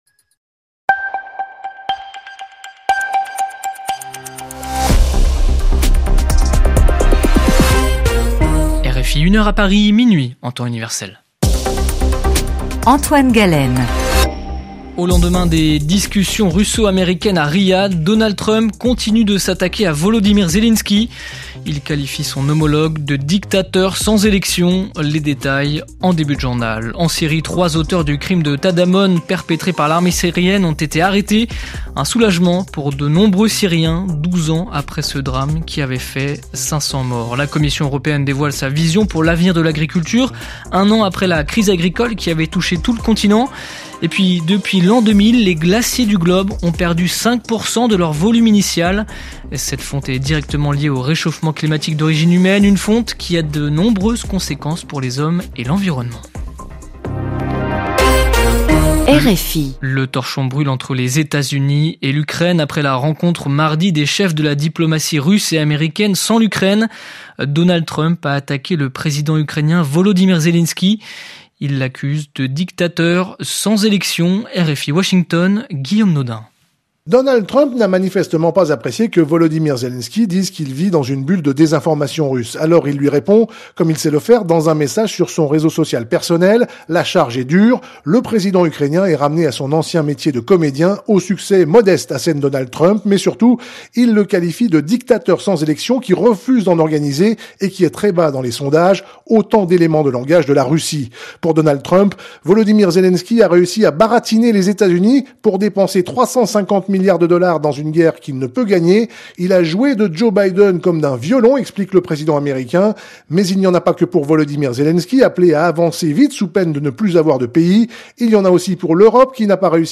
Retrouvez tous les journaux diffusés sur l’antenne de RFI pour suivre l’actualité internationale, africaine, politique, culturelle ou sportive.